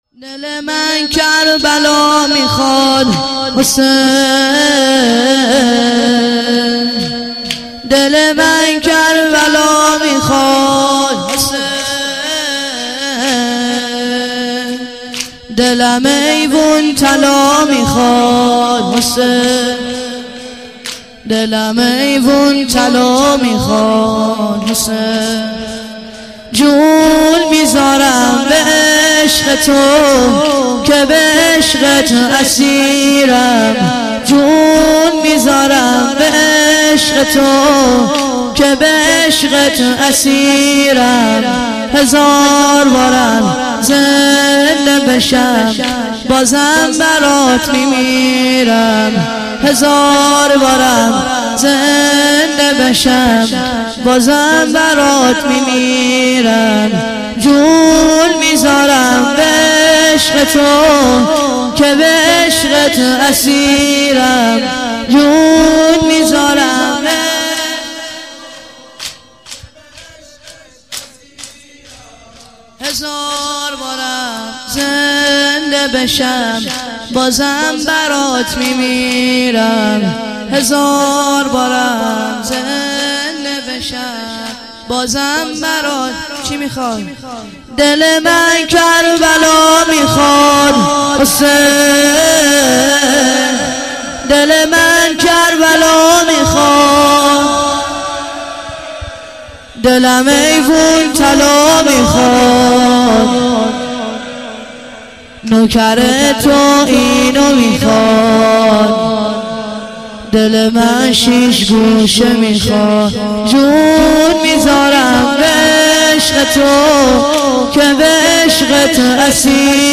چهار ضرب - دل من کربلا می خواد - مداح